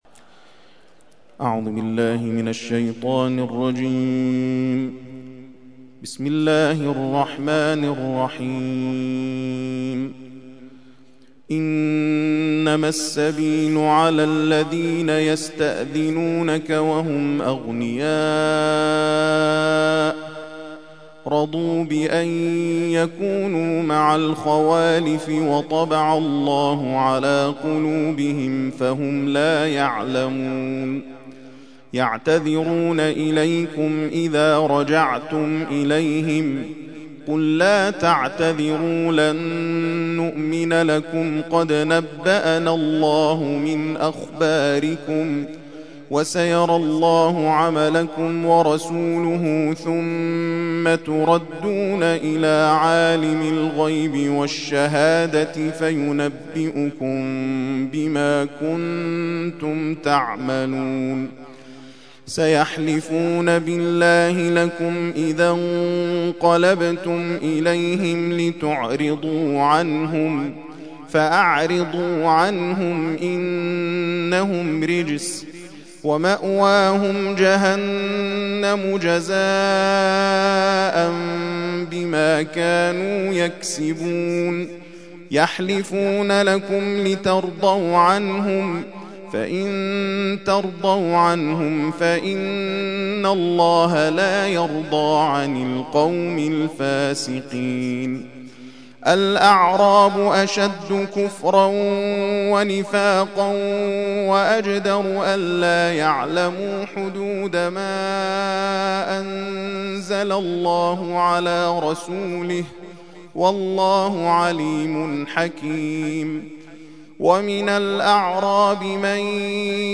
ترتیل خوانی جزء ۱۱ قرآن کریم در سال ۱۳۹۱